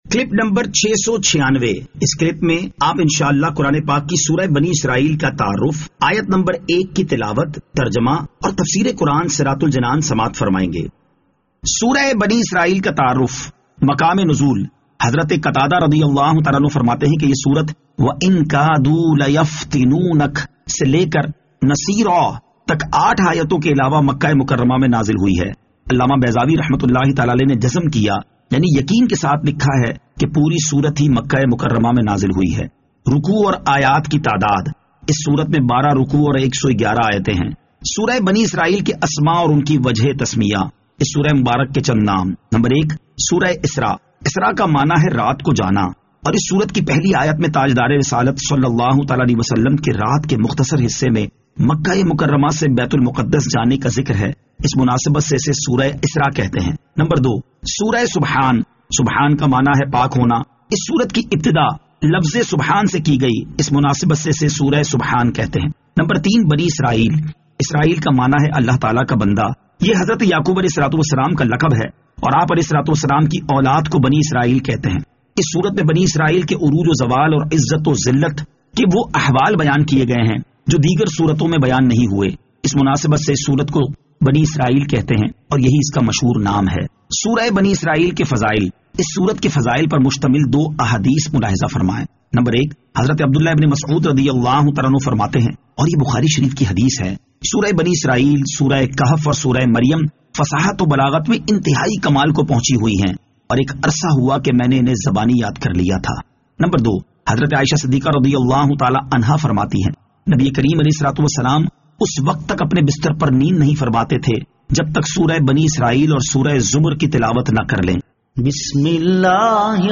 Surah Al-Isra Ayat 01 To 01 Tilawat , Tarjama , Tafseer